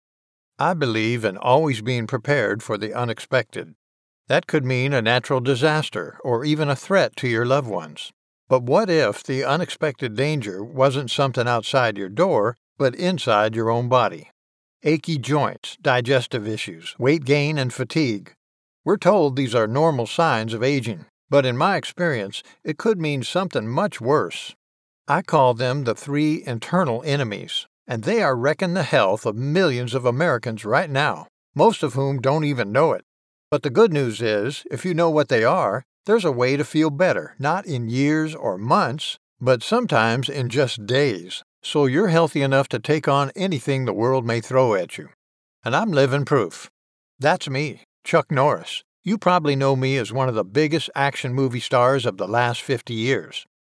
Demo
Mature Adult
southern us
Chuck_Norris-Texa_Style_southern_sample.mp3